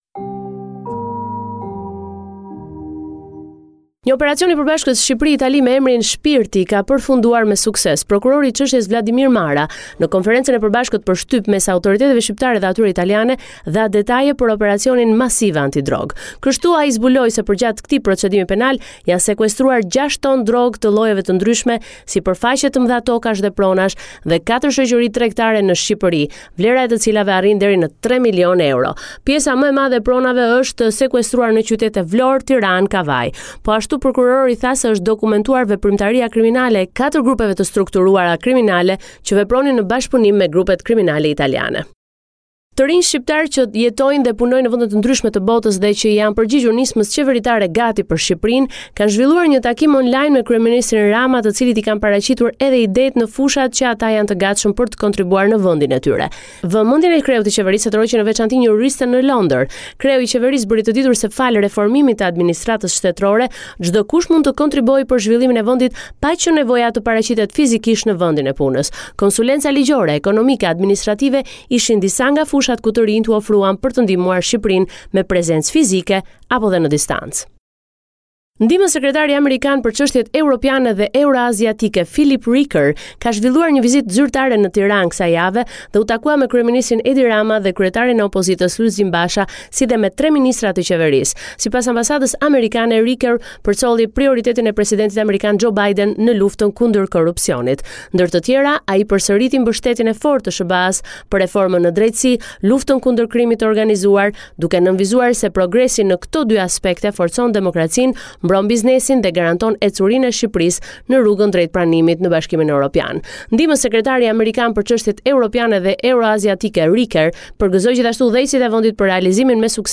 This is a report summarising the latest developments in news and current affairs in Albania.